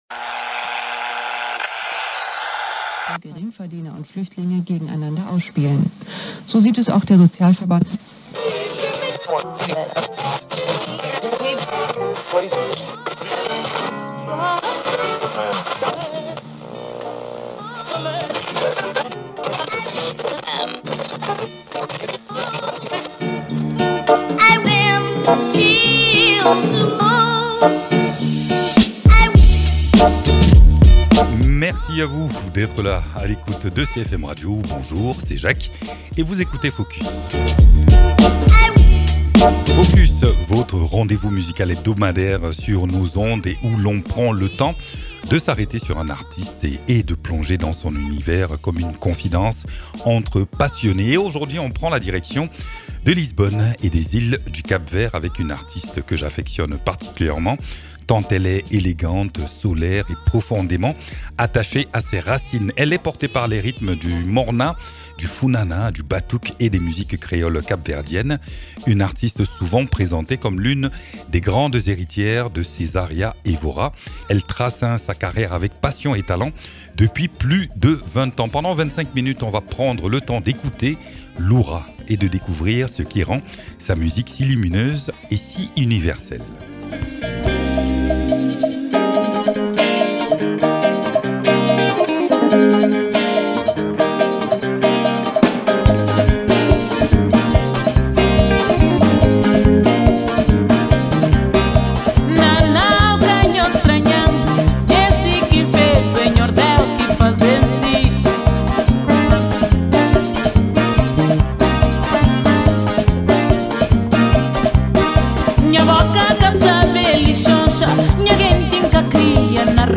Un voyage musical vibrant et lumineux !